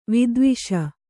♪ vidviṣa